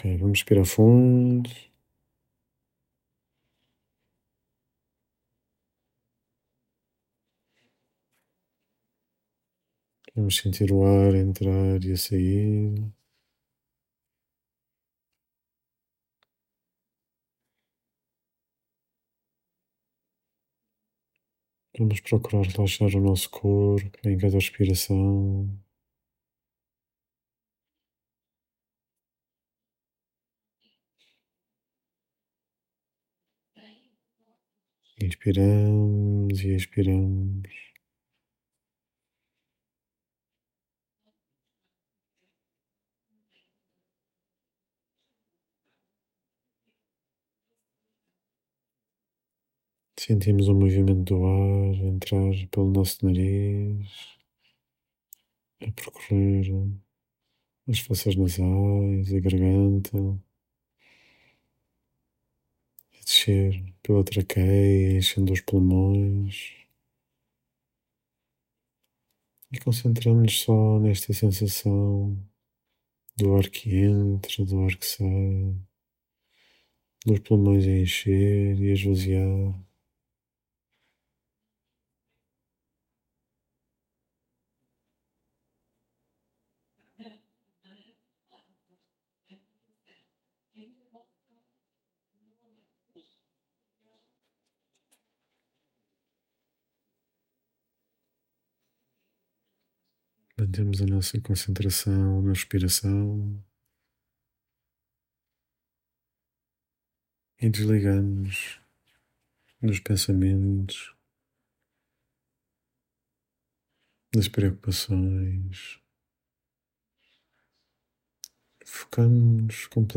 Meditação